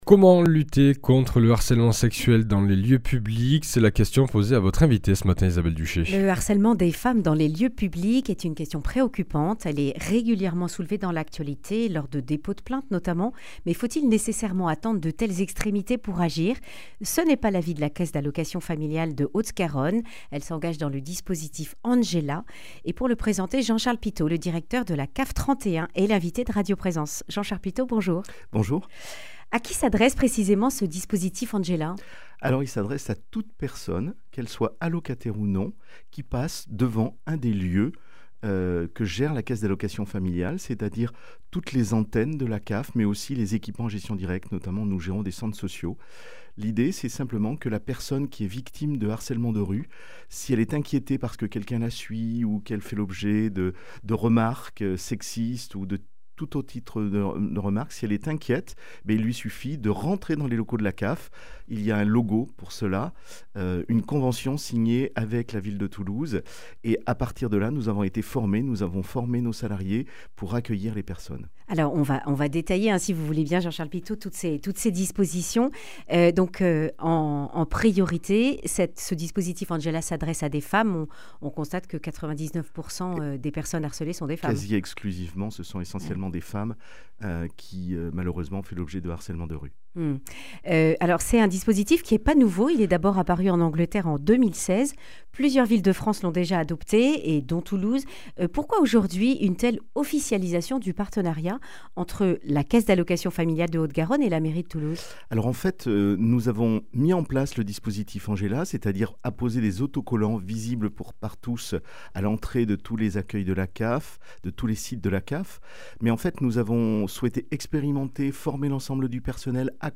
Accueil \ Emissions \ Information \ Régionale \ Le grand entretien \ Demandez Angela, le dispositif pour protéger les femmes victimes de (...)